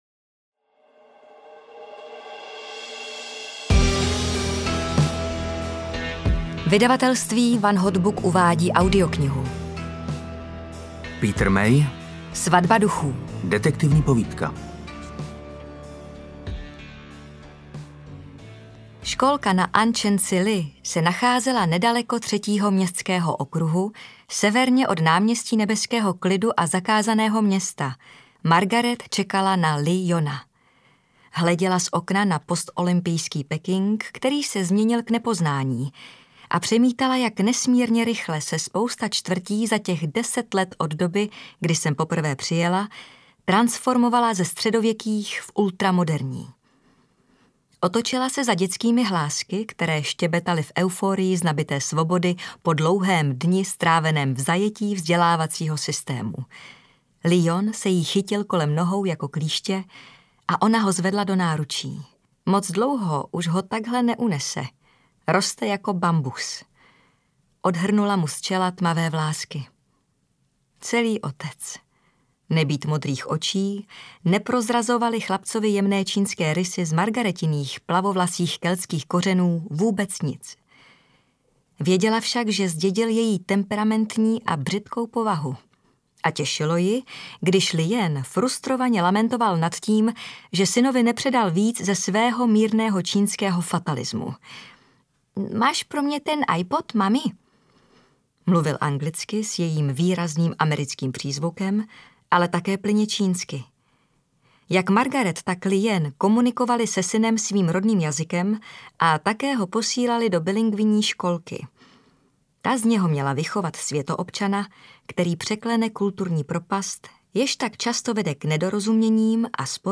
Svatba duchů audiokniha
Ukázka z knihy
• InterpretJana Plodková, Martin Myšička